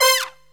SYNTH GENERAL-4 0007.wav